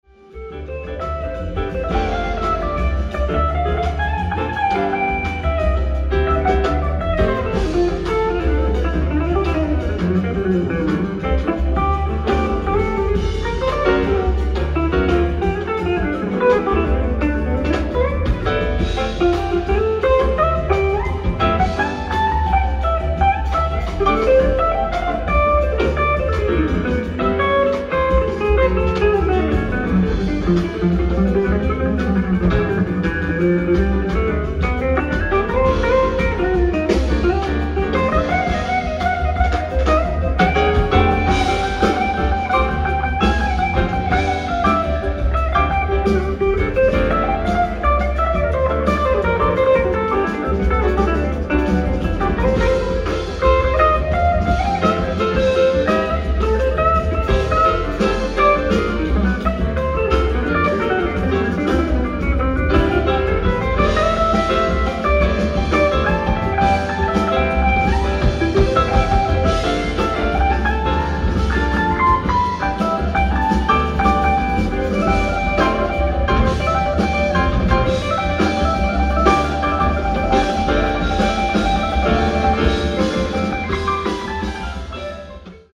ライブ・アット・ハミングバード・センター、トロント、カナダ 02/18/2005
※試聴用に実際より音質を落としています。